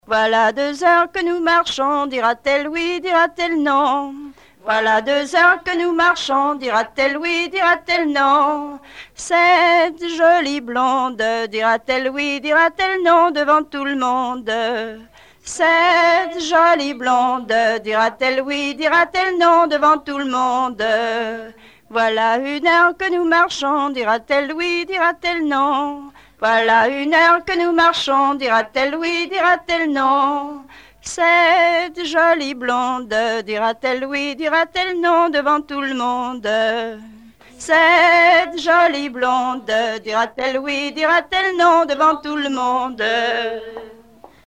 Genre énumérative
Chansons traditionnelles
Pièce musicale inédite